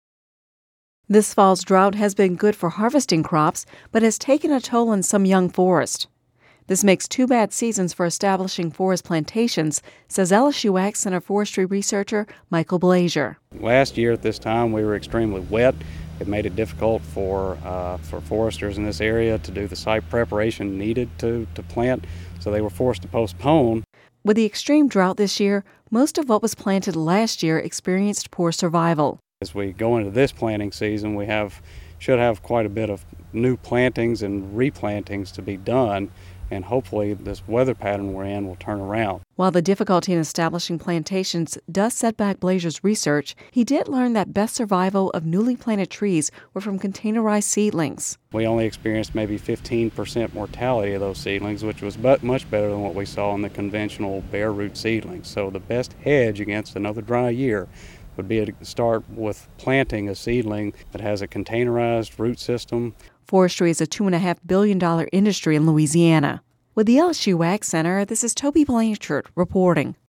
(Radio News 11/08/10) This fall’s drought has been good for harvesting some crops but has taken a toll on young pine trees.